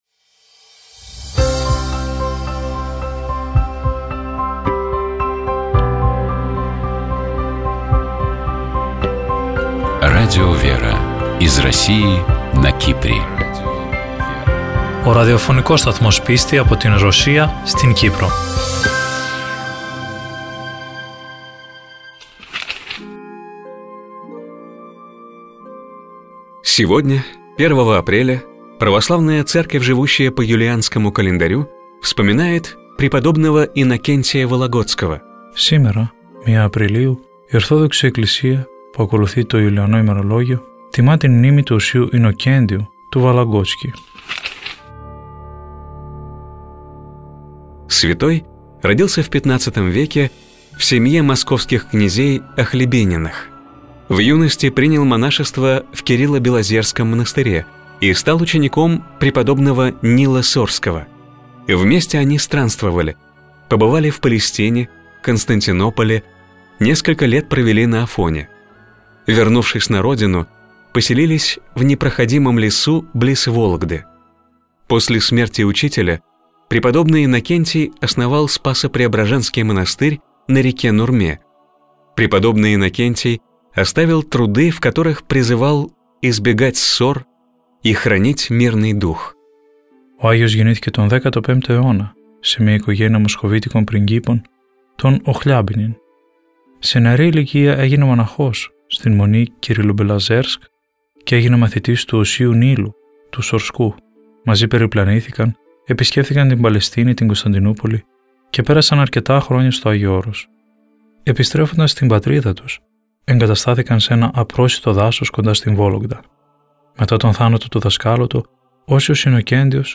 Известные актёры, режиссёры, спортсмены, писатели читают литературные миниатюры из прозы классиков и современников. Звучат произведения, связанные с утренней жизнью человека.